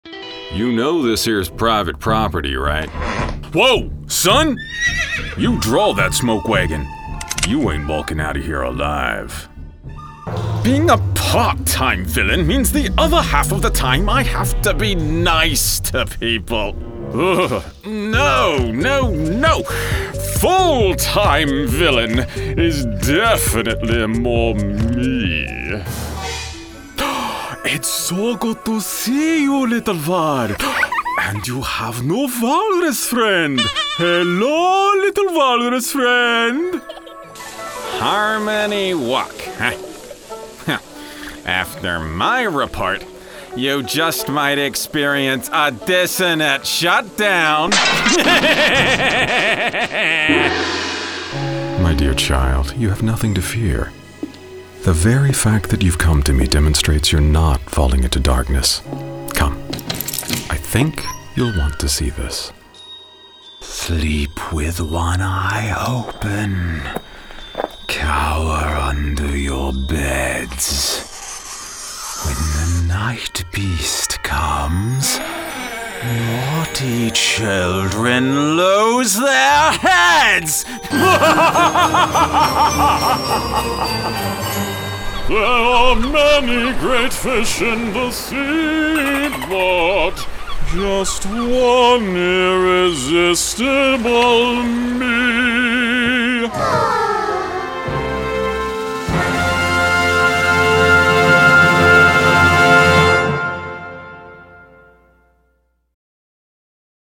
Character Demo